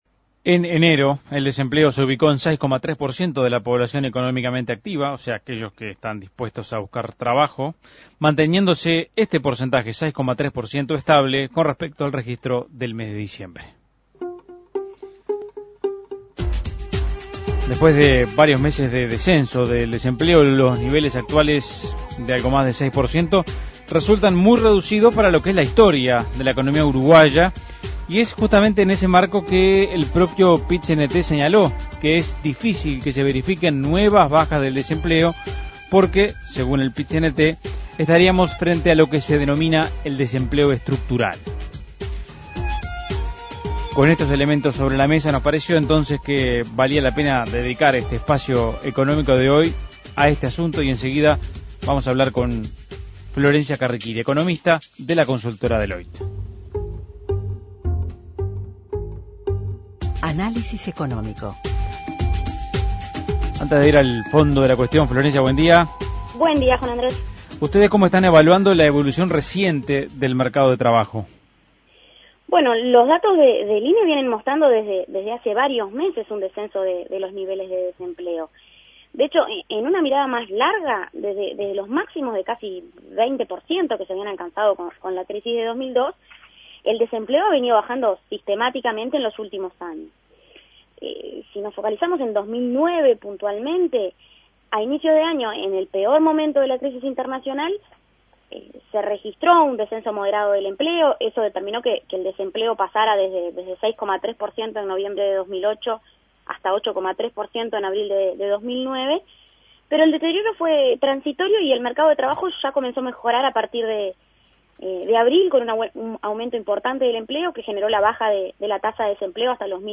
Análisis Económico El mercado de trabajo en Uruguay: ¿se alcanzó el desempleo estructural o la desocupación puede seguir bajando?